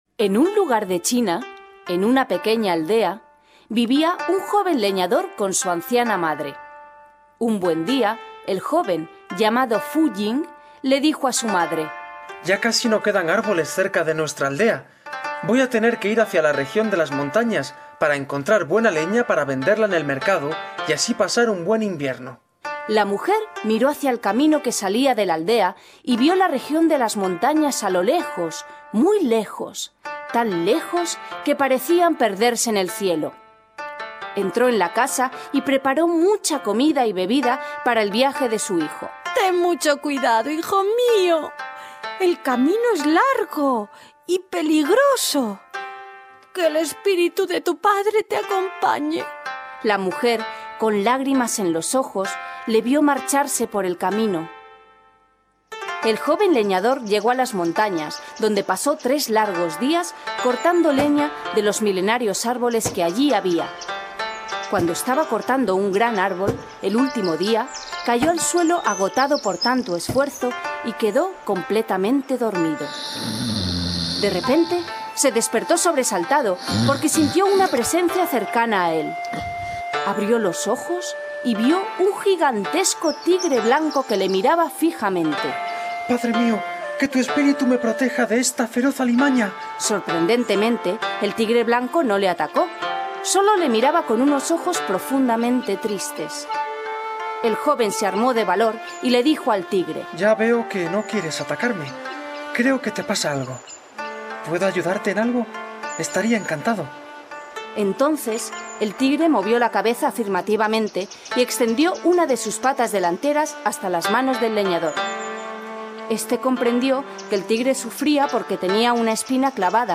Cuentos infantiles